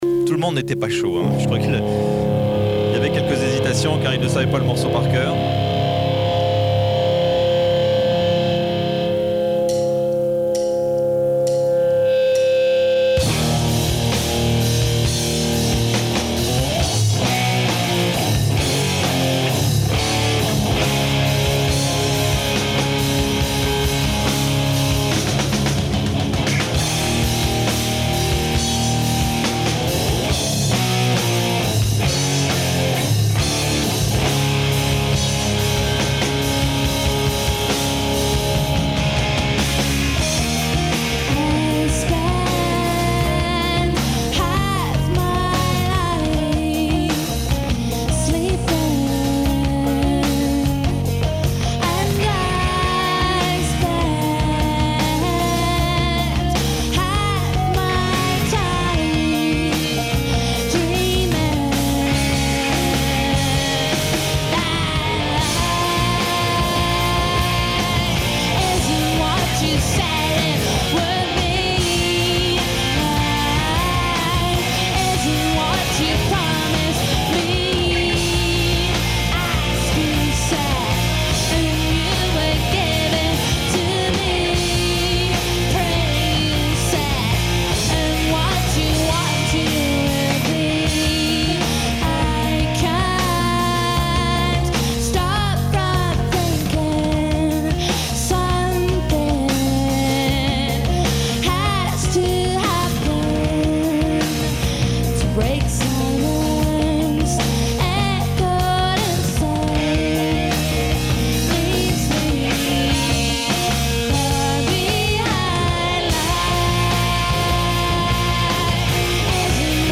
enregistrée le 28/05/1996  au Studio 105